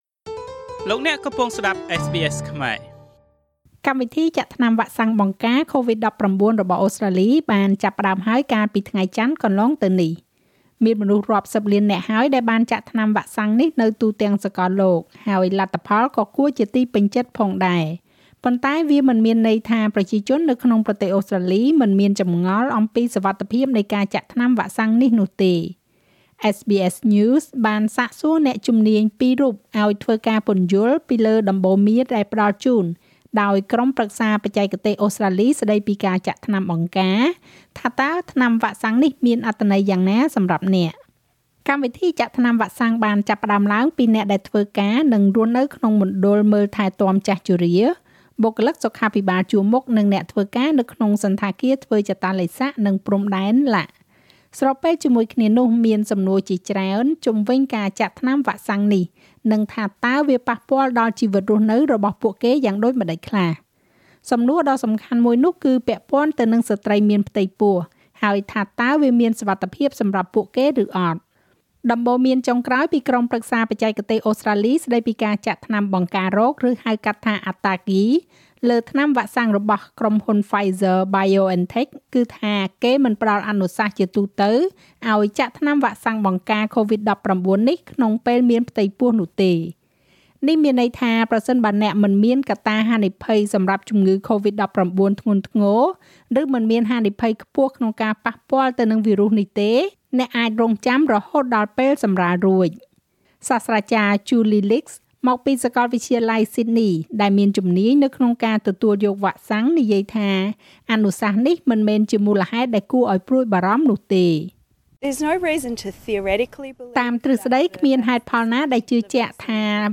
SBS News បានសាកសួរអ្នកជំនាញ២រូបឲ្យធ្វើការពន្យល់លើដំបូន្មានដែលផ្តល់ជូនដោយក្រុមប្រឹក្សាបច្ចេកទេសអូស្រ្តាលីស្តីពីការចាក់ថ្នាំបង្ការ ថាតើវ៉ាក់សាំងនេះមានអត្ថន័យយ៉ាងណាសម្រាប់អ្នក។